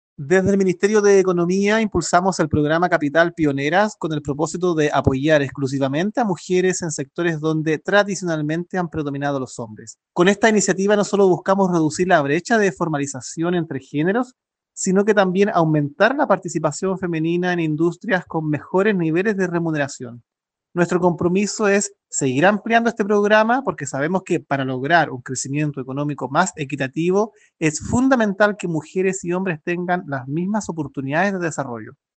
En Biobío, el seremi de Economía, Fomento y Turismo, Javier Sepúlveda, se refirió a la importancia de este proyecto para acortar la brecha de género en el rubro económico.